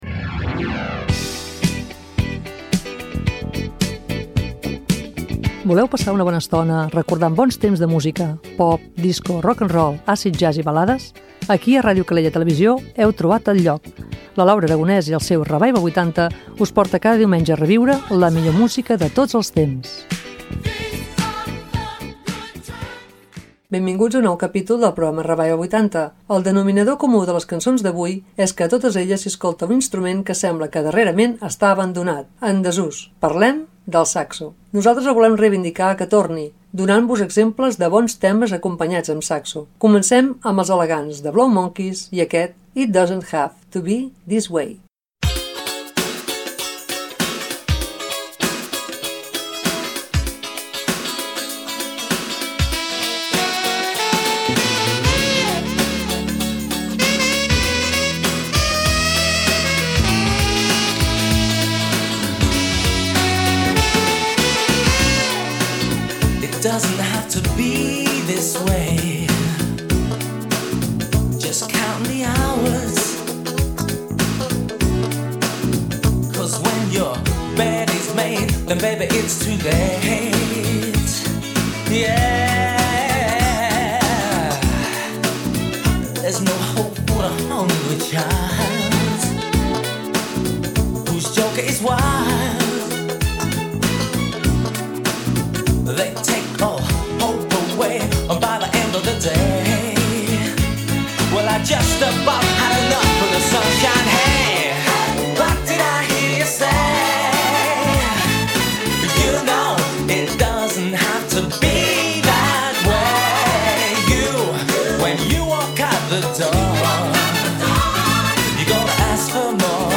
De tots els instruments en la música pop, uns dels més oblidats fa uns quants anys és el saxo. Des d'aquí el "Revival 80" el volem reinvindicar i demanar que es torni a posar de moda, doncs sempre hi posa un toc d'elegància a les cançons.
Revival 80 – Cançons amb saxo